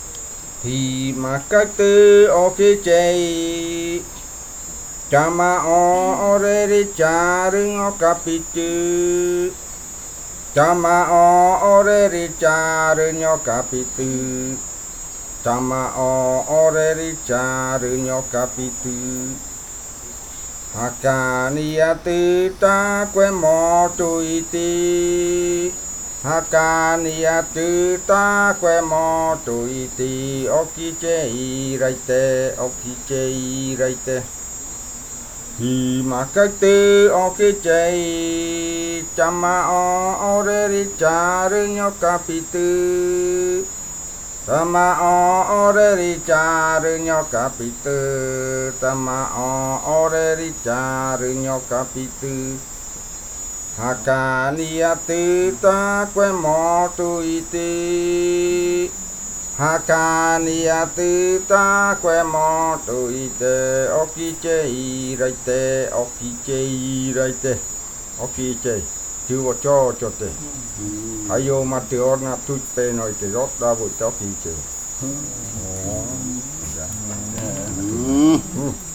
Leticia, Amazonas, (Colombia)
Grupo de danza Kaɨ Komuiya Uai
Canto de fakariya de la variante jaiokɨ (cantos de culebra).
Fakariya chant of the Jaiokɨ variant (Snake chants).